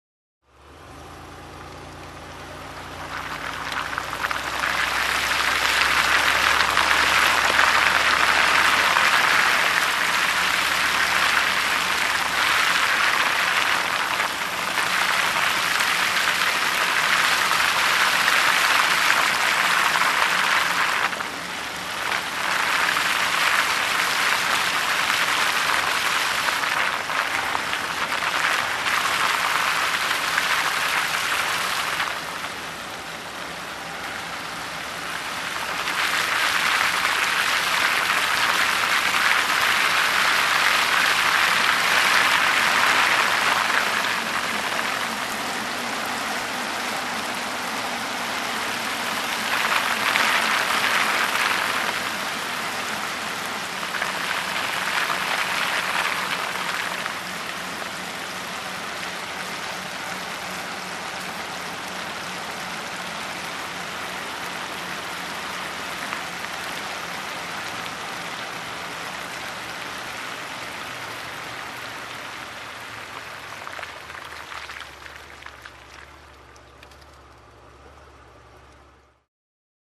Звуки машины, снега
Слякоть от тающего снега